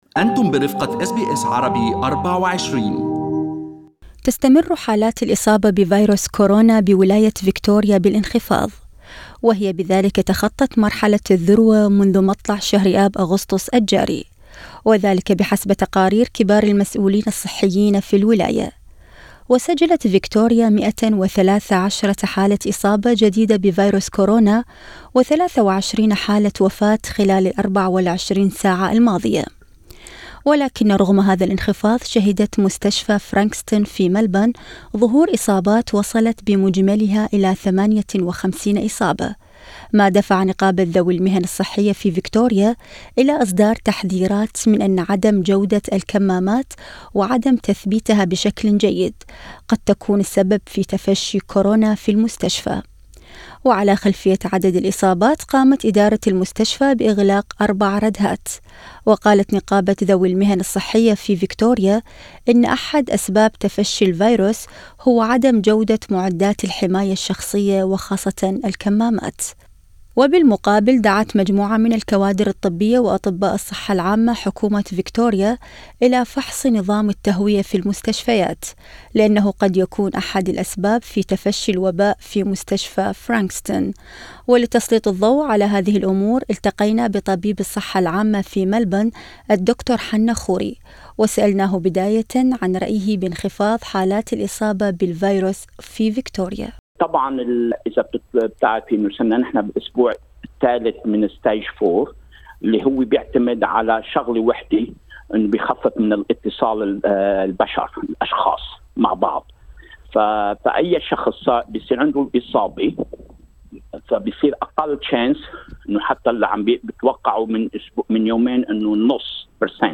التقينا بطبيب الصحة العامة في ملبورن